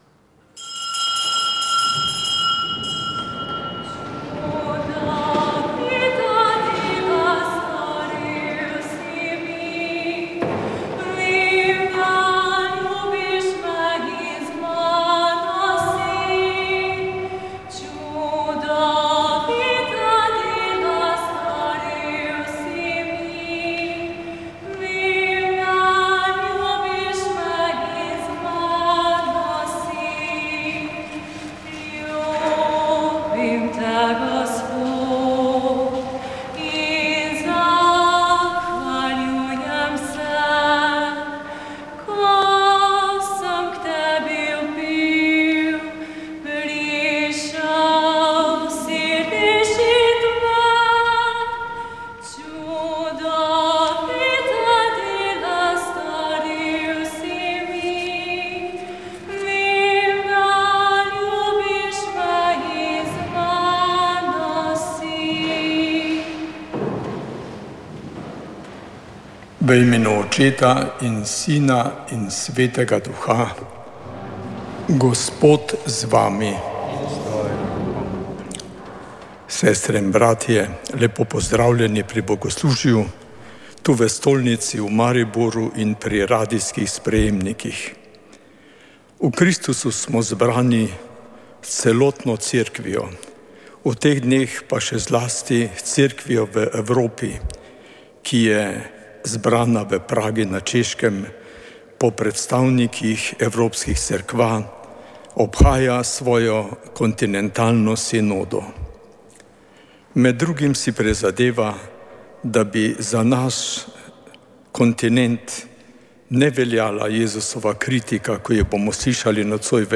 Sveta maša
Sv. maša iz stolne cerkve sv. Janeza Krstnika v Mariboru 7. 2.